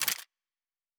Weapon 09 Foley 1.wav